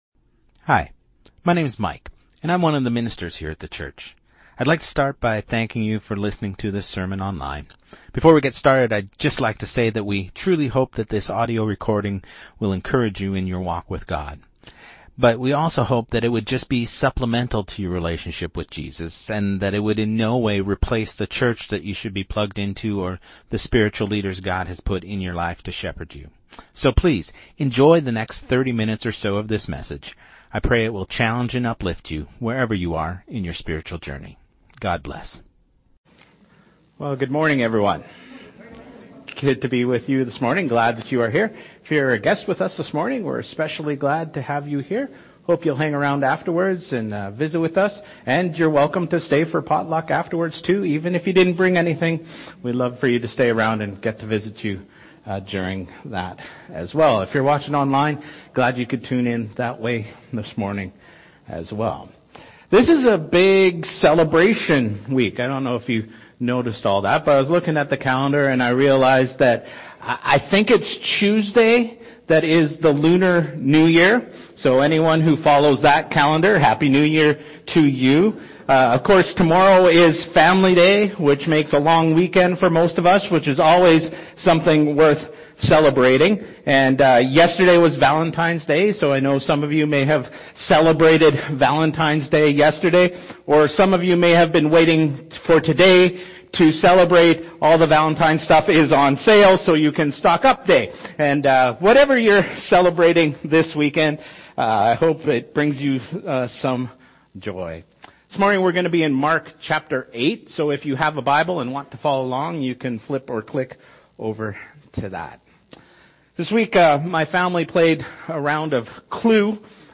Sermon2026-02-15